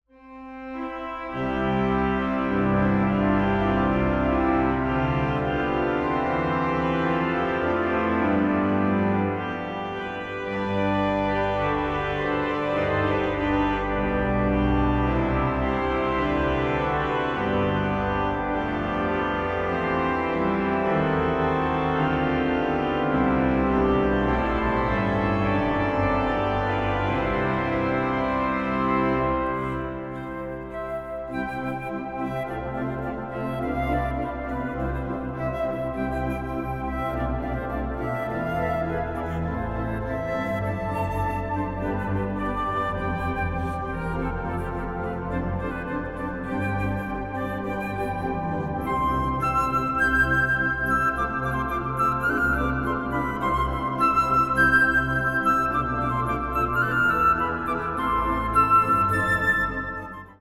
Instrumentale Koraalbewerkingen